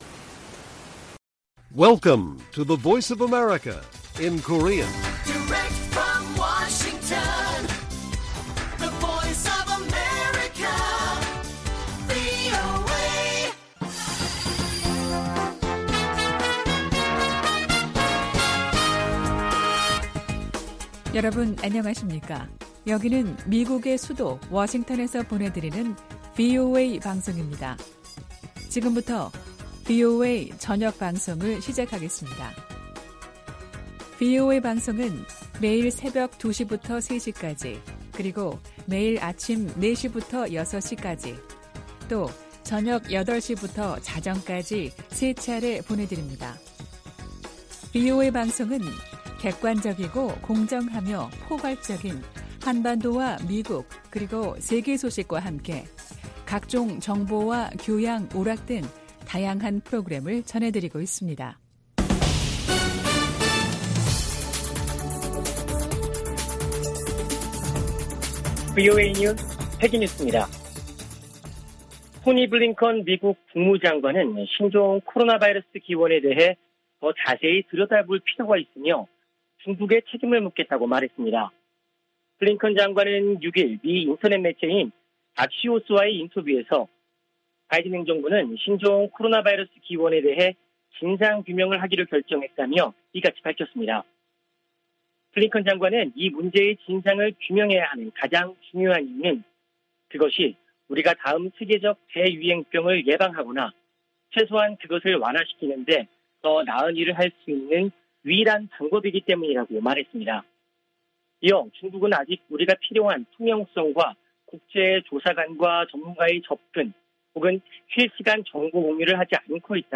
VOA 한국어 간판 뉴스 프로그램 '뉴스 투데이' 1부 방송입니다.